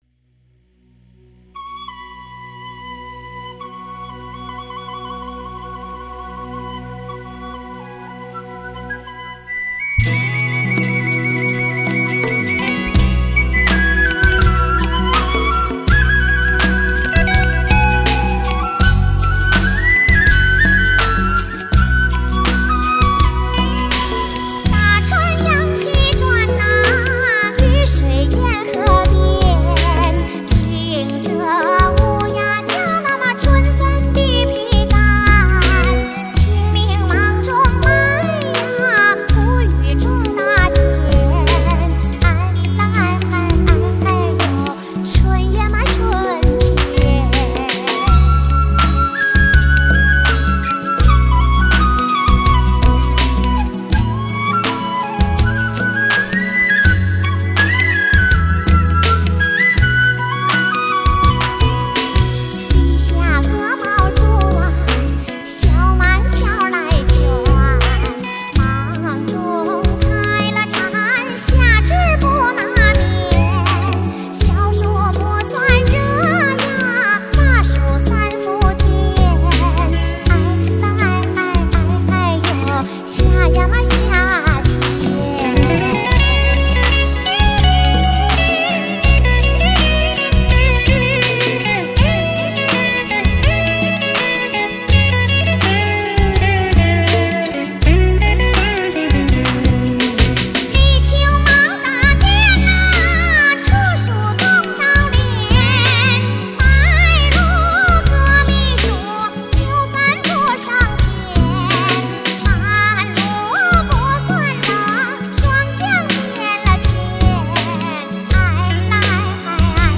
[10/3/2018]阎学晶演唱的东北民歌《二十四节气歌》